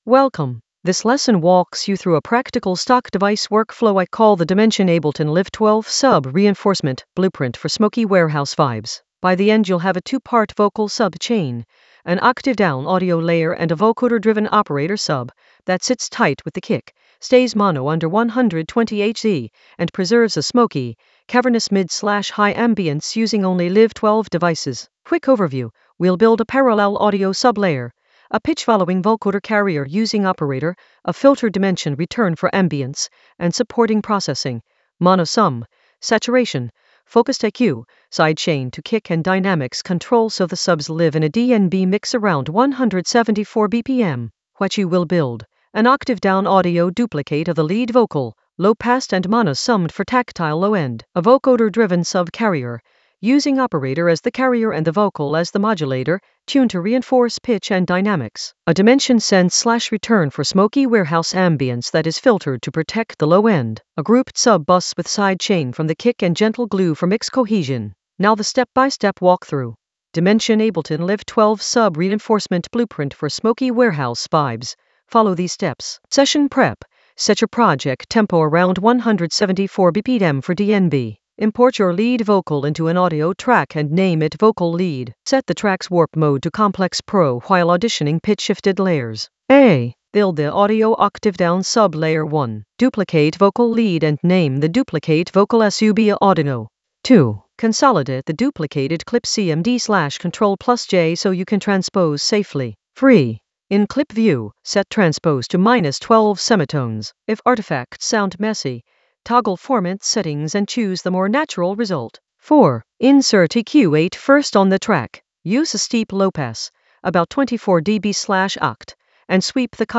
An AI-generated intermediate Ableton lesson focused on Dimension Ableton Live 12 sub reinforcement blueprint for smoky warehouse vibes in the Vocals area of drum and bass production.
Narrated lesson audio
The voice track includes the tutorial plus extra teacher commentary.